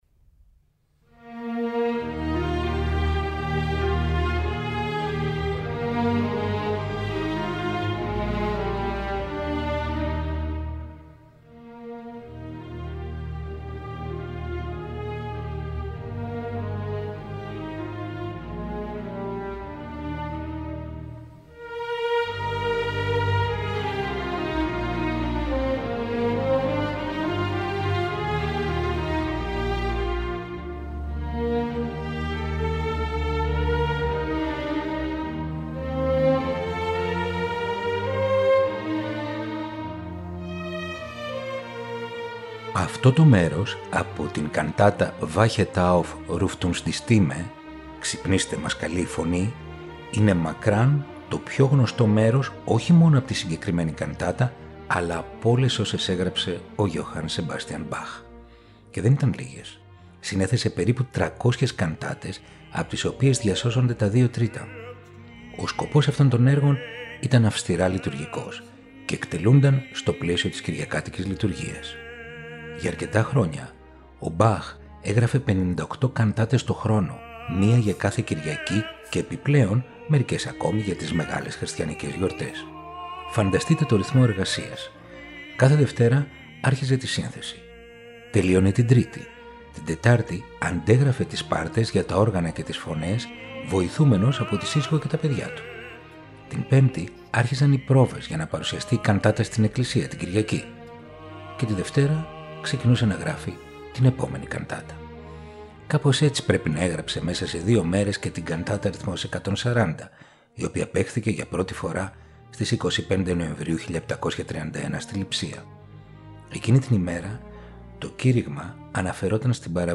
Η τζαζ συναντά την κλασσική μουσική